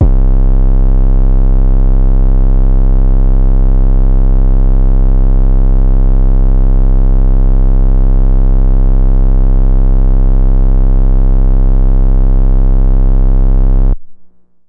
Ratchet 808 SSL (C).wav